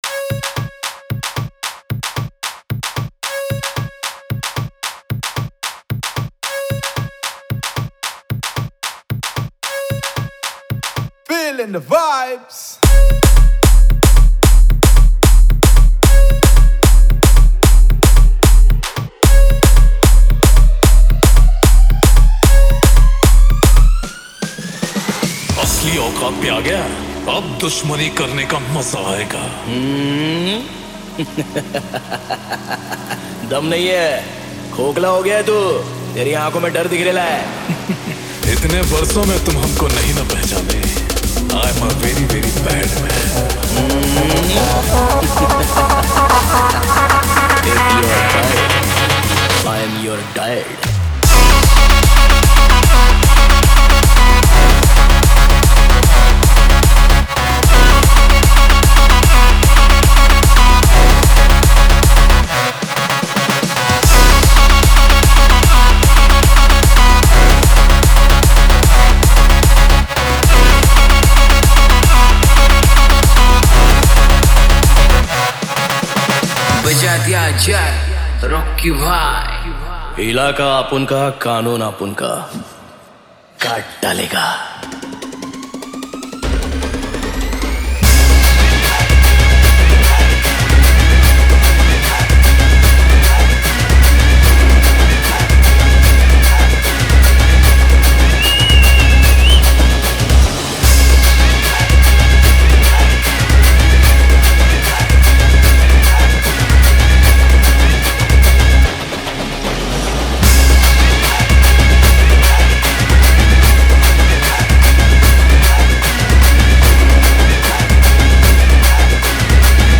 Category : Single DJ Remix Songs